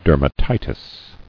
[der·ma·ti·tis]